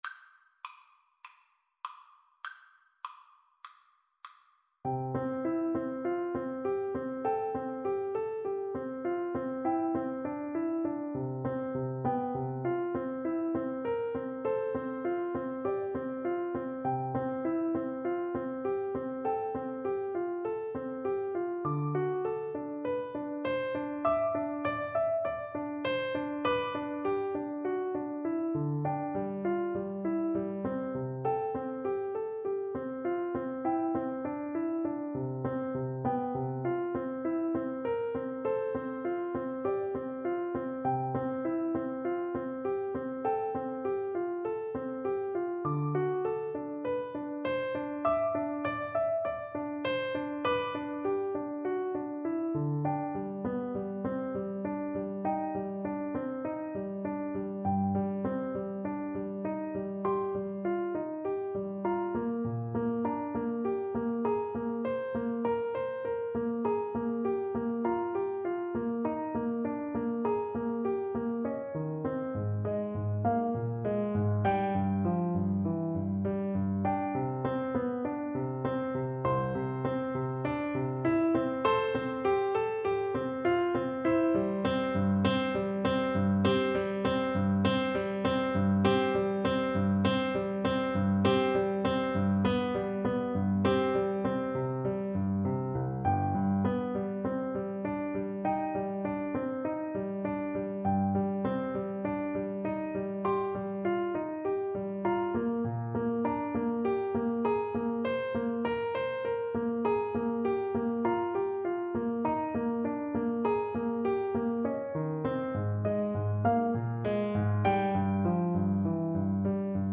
• Unlimited playalong tracks
4/4 (View more 4/4 Music)
C major (Sounding Pitch) (View more C major Music for Oboe )
Classical (View more Classical Oboe Music)